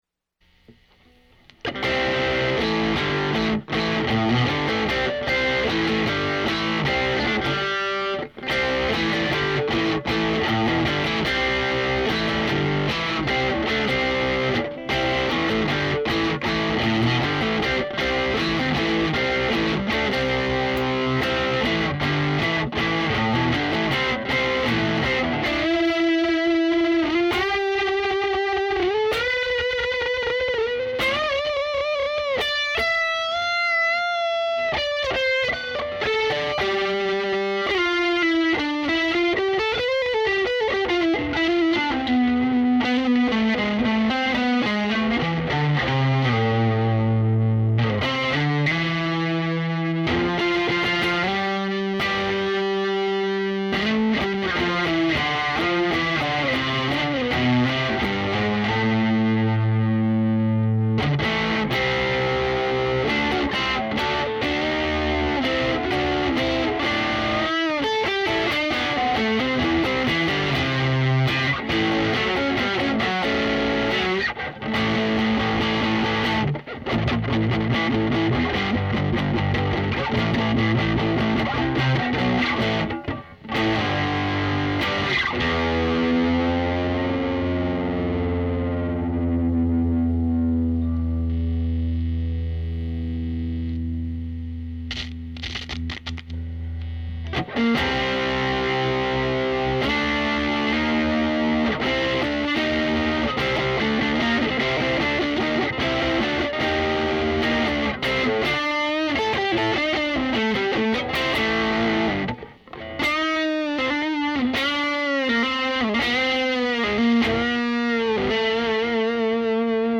Not really playing .... just making sounds to hear how it reacts.
These might be anything from regular guitar tones to weird sound effects one of my obscure pedals might make.
That means these recordings won't be done with a mic carefully placed but they should capture the essence of whatever I have.
It is an amp .... not a sim.